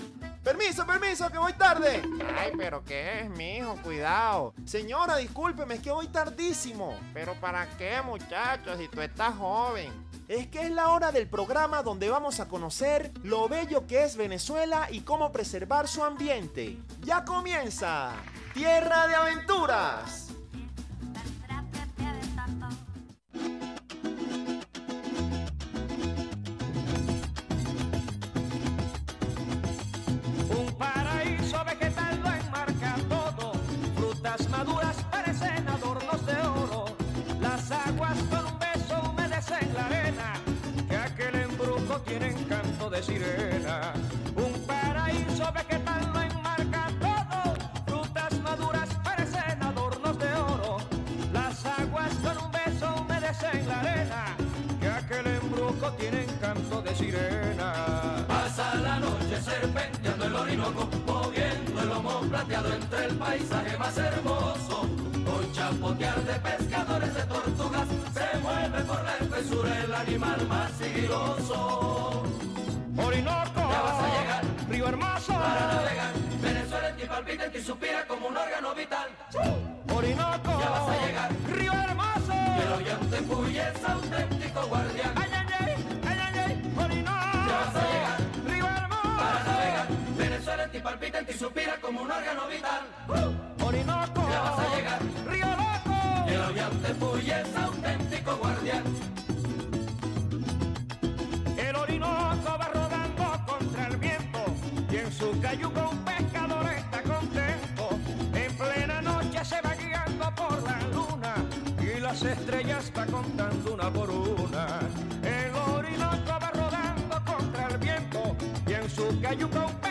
Programa de ecoturismo, con música fresca y alegre, donde se dará a conocer la variedad de destinos turísticos que hay en Venezuela.